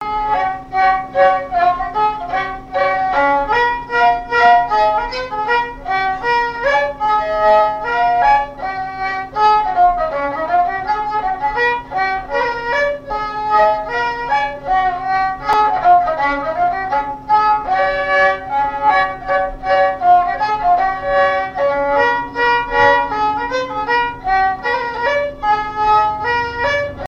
Chants brefs - A danser
danse : scottich sept pas
Répertoire de marches de noce et de danse
Pièce musicale inédite